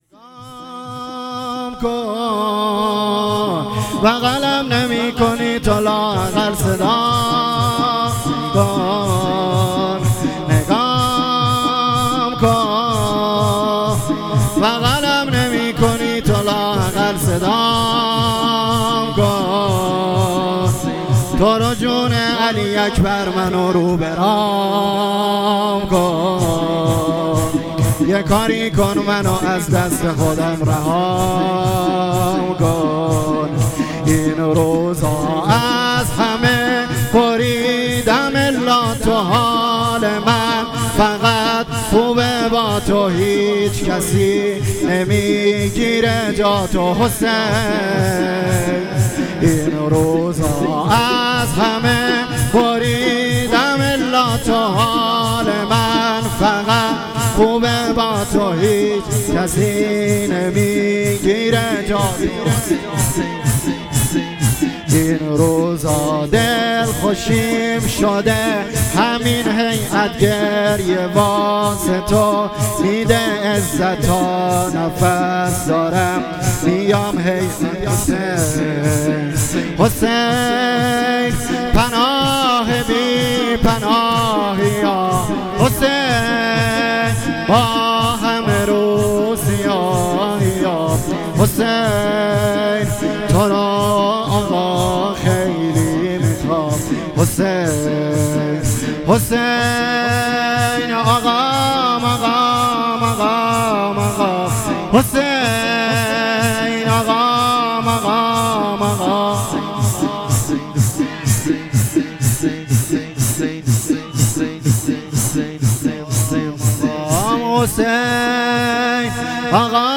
شور | بغلم نمیکنی تو لااقل صدام کن
شب سوم شهادت امام علی (ع) |هیئت میثاق با شهدا